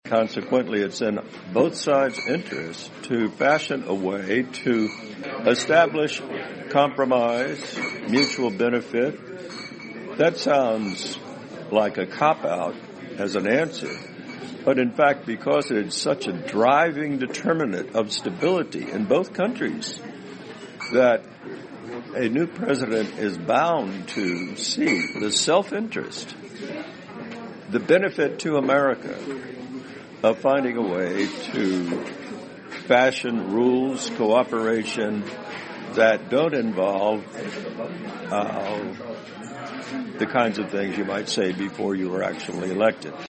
VOA专访里根国安顾问麦克法兰(3)